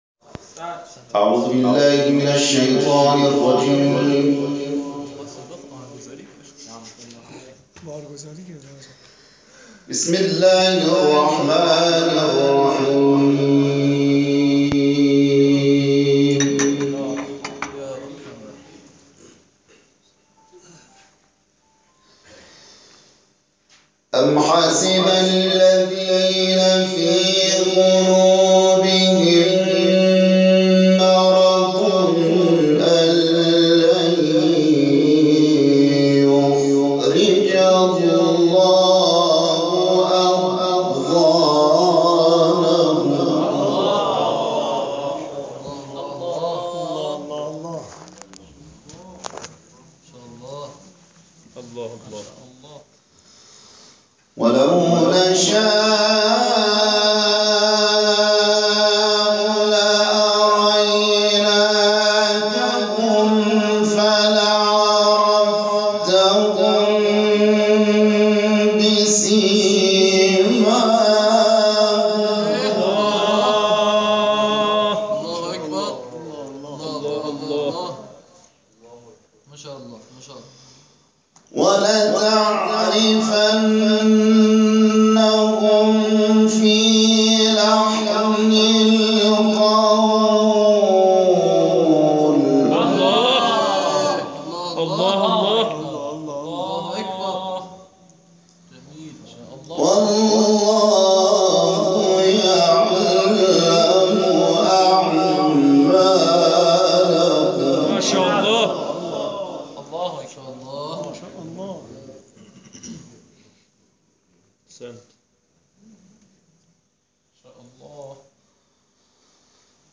تلاوت
قاری بین المللی ، سوره محمد